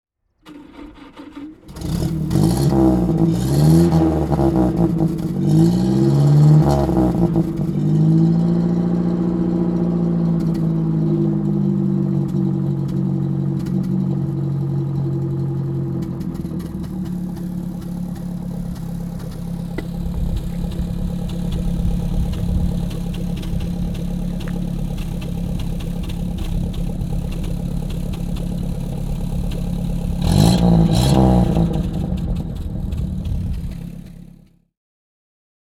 Citroën 11 BL von 1954 - Starten und Standgeräusch